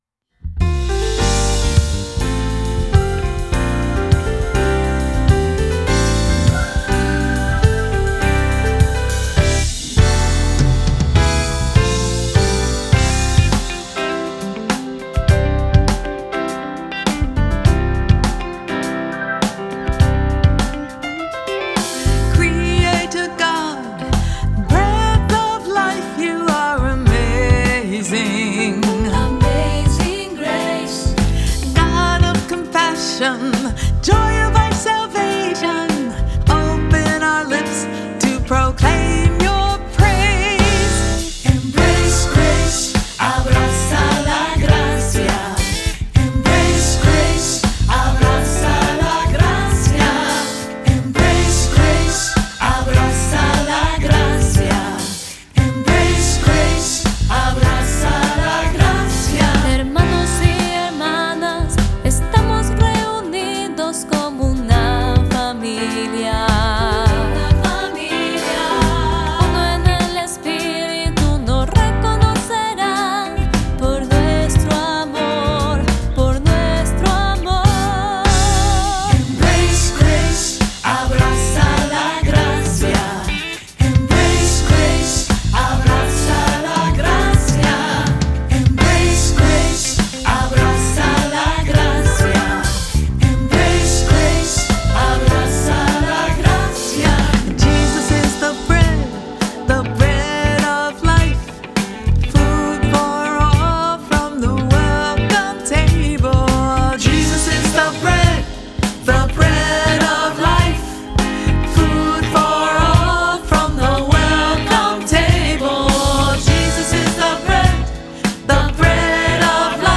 Voicing: "SAB","Cantor","Assembly"